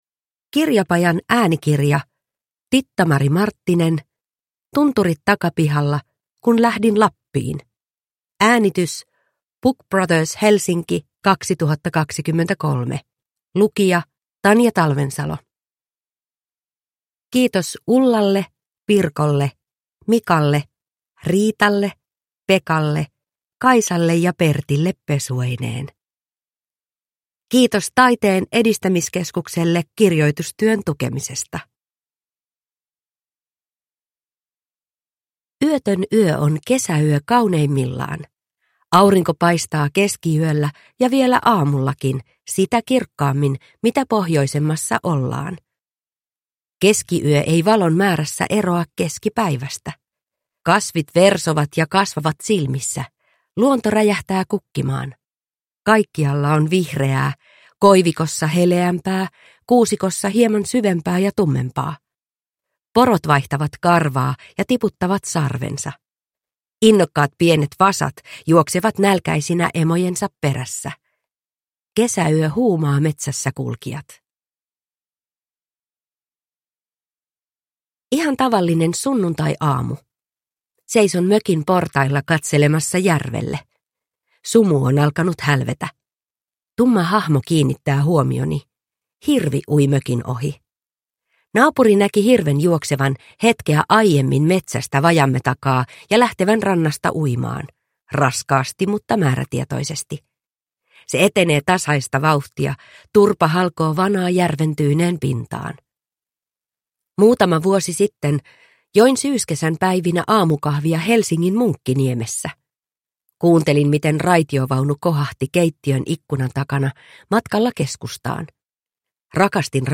Tunturit takapihalla – Ljudbok